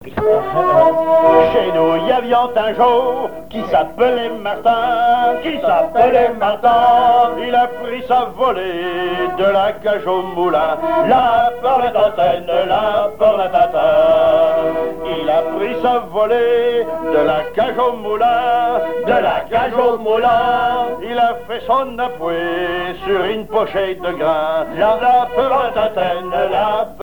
Herbiers (Les)
Genre laisse
Pièce musicale inédite